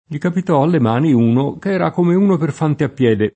l’i kapit0 alle m#ni 2no k $ra k1me 2no per f#nte a ppL$de] (Sacchetti) — raro fantappiede [fantappL$de]: fantappiedi, cavalieri e soldati di mare [